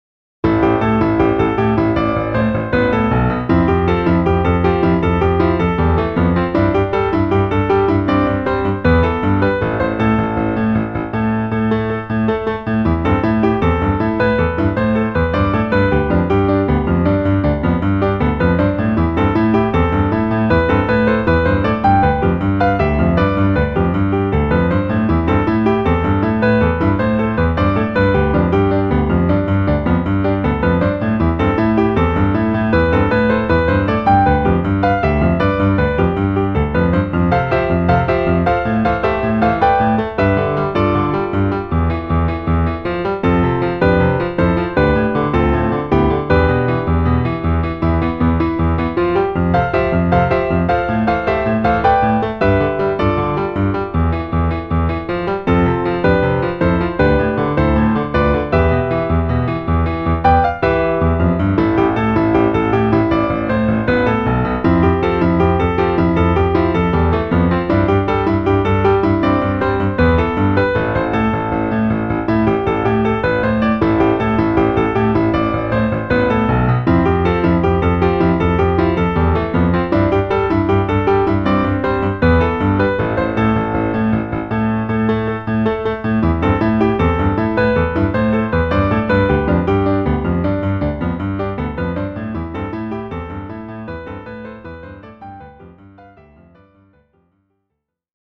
Ivory 3 German D